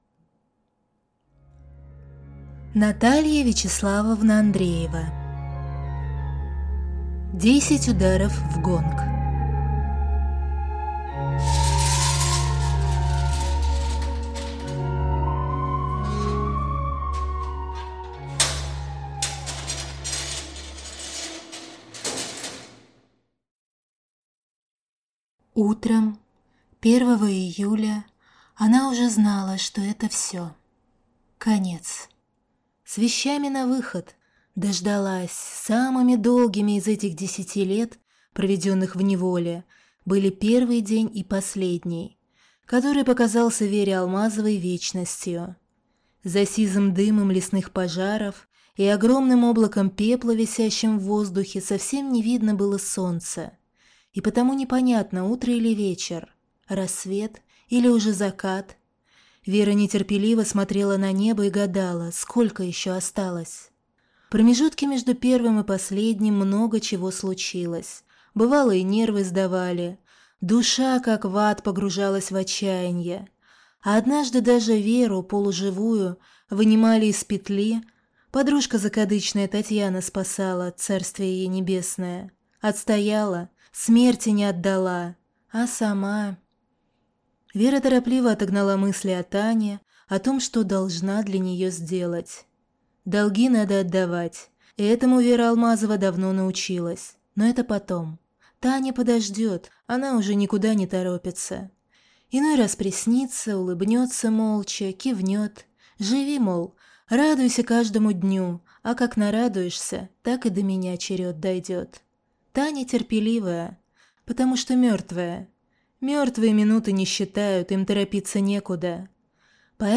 Аудиокнига Десять ударов в гонг | Библиотека аудиокниг